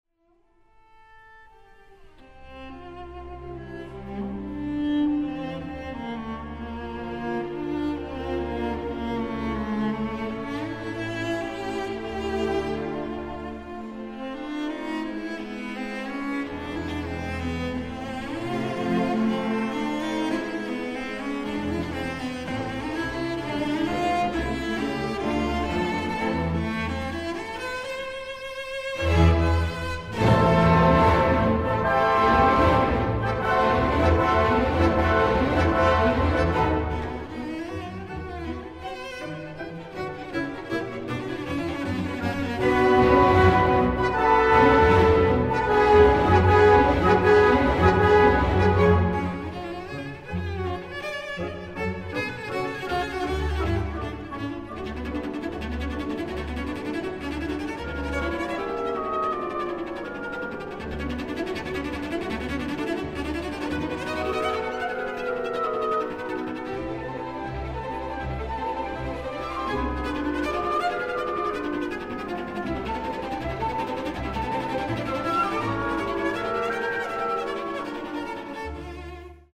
Concierto para violonchelo no. 1 Camille Saint-Saëns
Formalmente el concierto es una extensión de la técnica que Saint-Saëns había usado en su Concierto para violín en la mayor de 1859. En vez de usar la forma común de tres movimientos, estructuró la obra en un solo movimiento continuo, sin pausas.